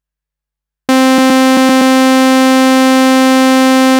I made the test with the 505 and can confirm that it fluctuates at start.
so the same perfectly recorded saw that I recorded in the 505 yesterday, and played back flawlessly yesterday, was all choppy and fluctuating today!
so, just for the record. here is exactly the same I did last time, only the 505 decided different today